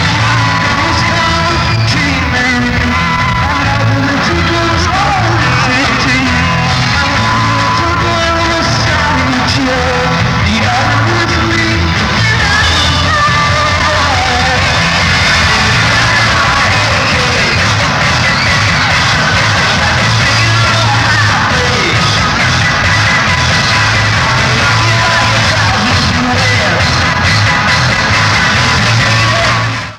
Format/Rating/Source: CD - D - Audience
Comments: Rare concert material, very poor sound quality.
Rochester '67
* Compression added to enhance the sound quality